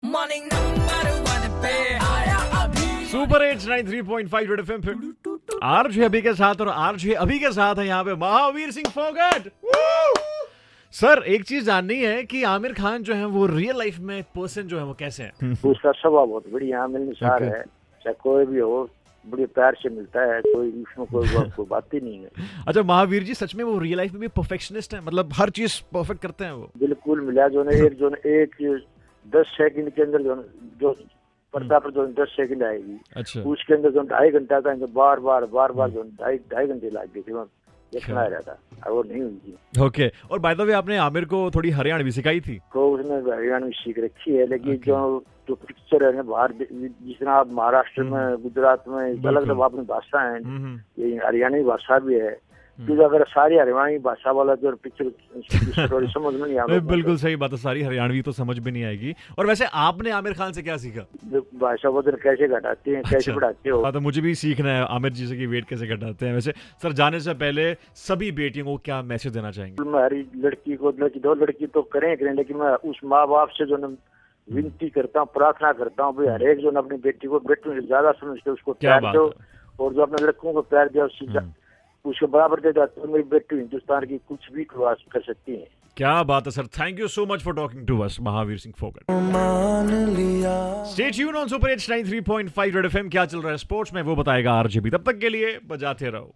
Mahavir Singh Phokat - Real Life Dangal in concersation